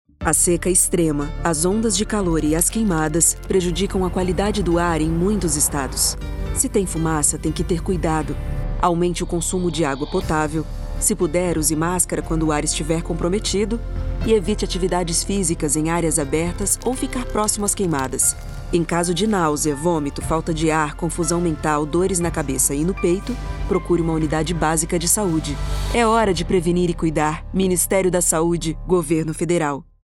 Áudio - Spot 30s - Queimadas - 1.1mb .mp3 — Ministério da Saúde